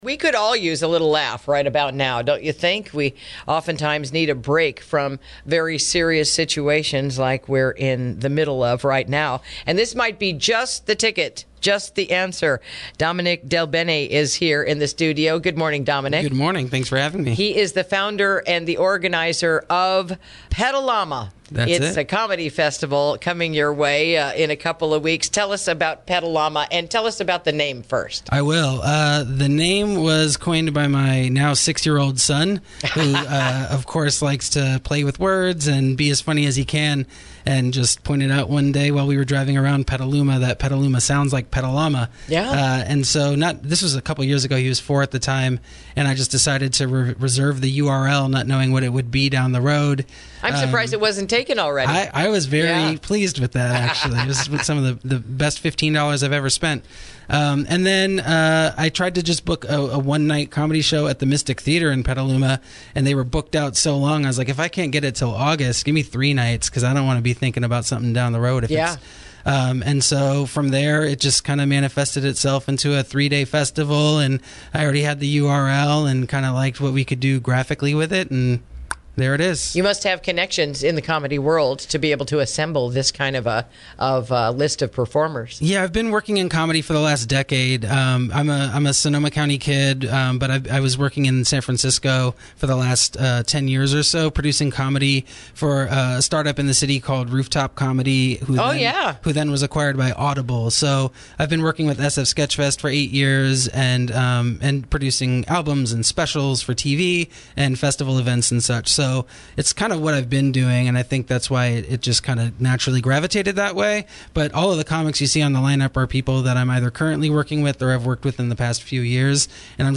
INTERVIEW: The Pet-A-Llama Comedy Festival is Coming Soon